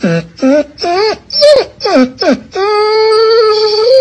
Whining Dog